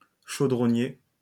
terme prononcé : chaudronnier
LL-Q150_(fra)-Visiteur_Journée_2_-_8_(Madehub)-chaudronnier.mp3